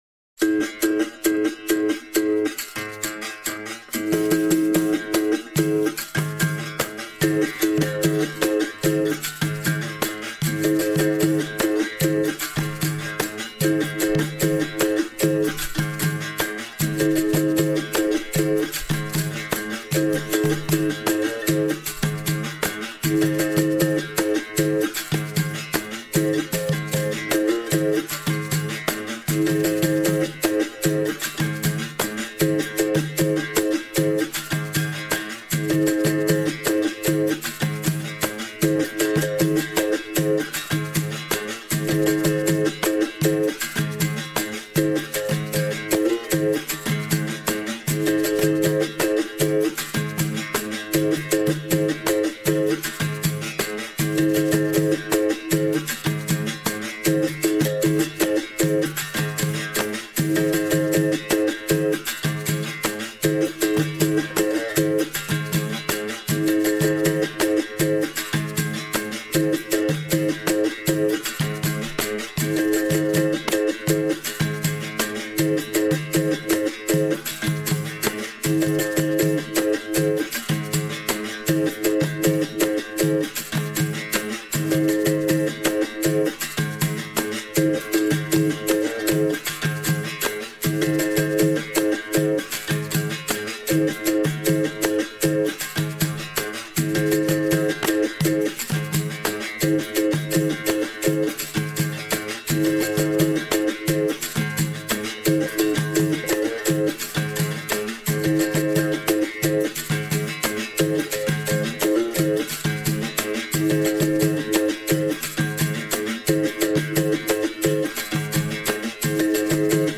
toque-de-iuna-na-capoeira-angola_1_.mp3